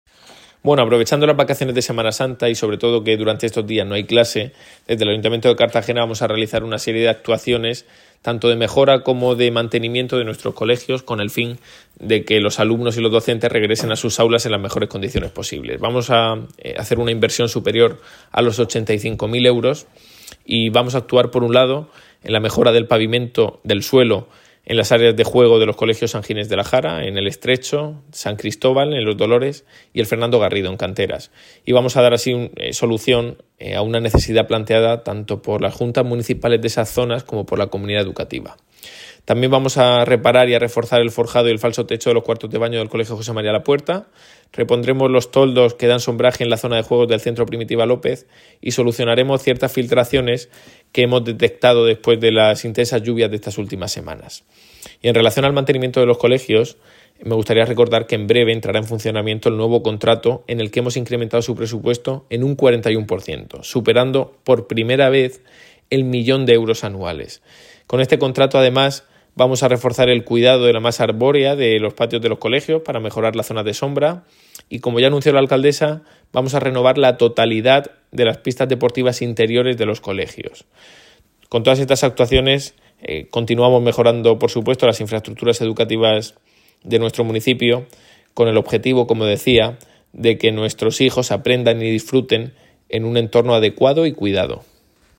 Enlace a Declaraciones de Ignacio Jáudenes sobre obras en colegios en Semana Santa